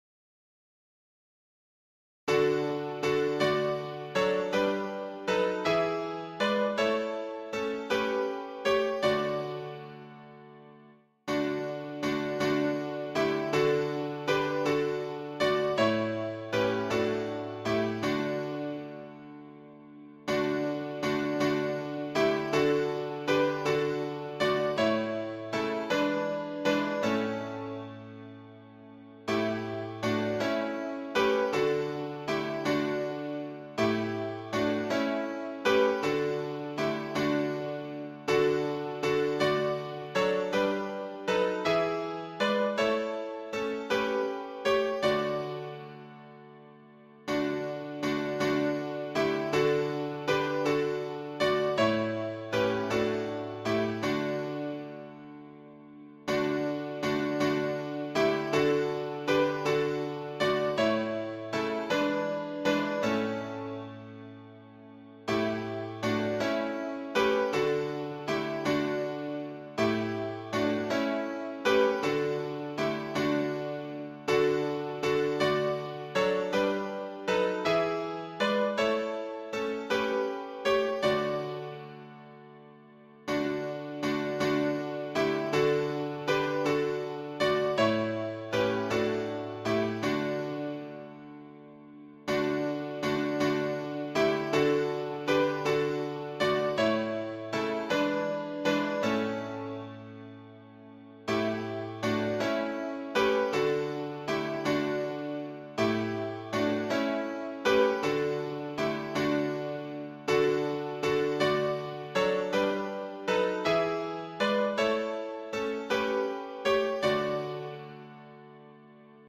伴奏
示唱